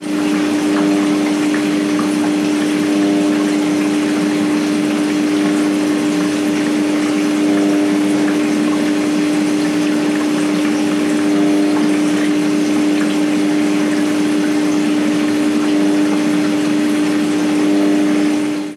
Ruido de una lavadora cogiendo agua
Sonidos: Agua
Sonidos: Hogar